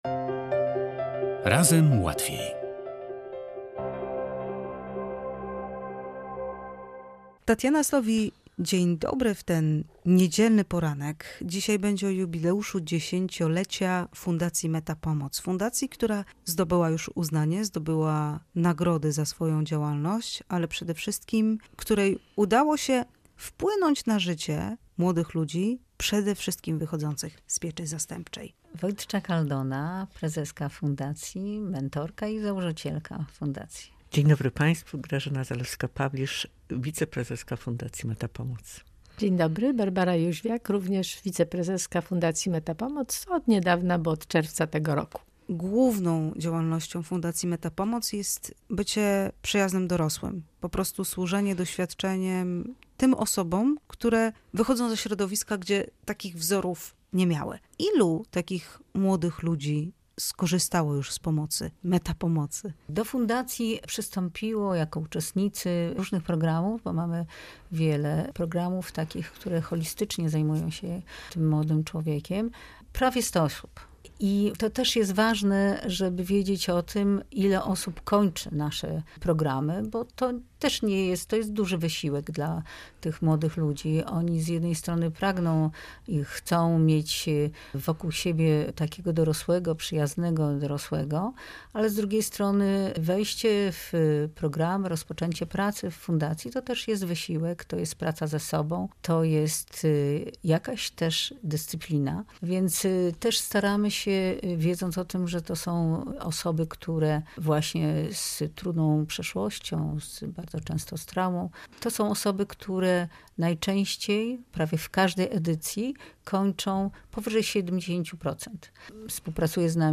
W studiu Radia Gdańsk